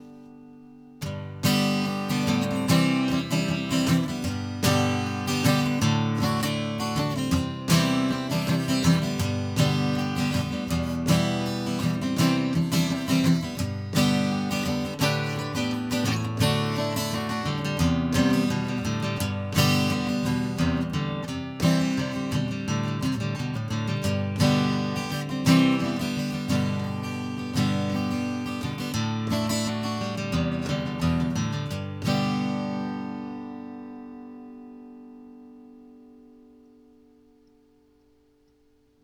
Я опубликую несколько позже, но вот еще один звуковой клип, короткий и маленький файл WAV, показывающий "бренчание" звука из устаревшего сырья ...